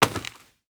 Dirt footsteps 17.wav